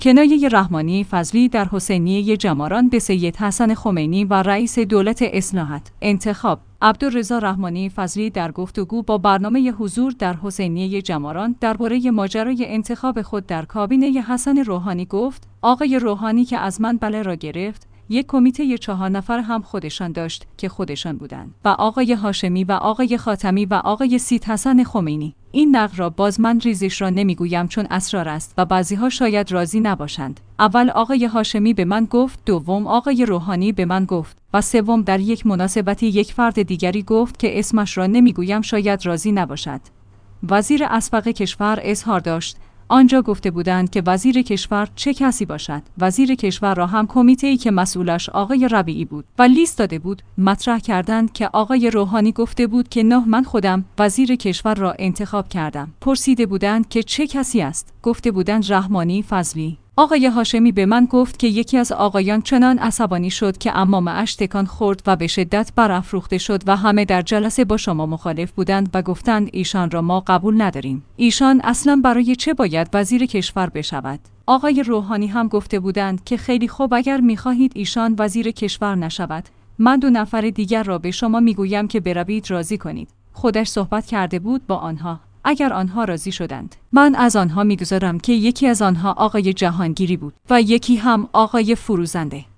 کنایه‌ی رحمانی‌فضلی در حسینیه‌ جماران به سید حسن خمینی و رئیس دولت اصلاحات
انتخاب/ عبدالرضا رحمانی فضلی در گفت وگو با برنامه حضور در حسینیه‌ی جماران درباره ماجرای انتخاب خود در کابینه حسن روحانی گفت: آقای روحانی که از من بله را گرفت، یک کمیته‌ چهار نفره هم خودشان داشت که خودشان بودند و آقای هاشمی و آقای خاتمی و آقای سیدحسن خمینی.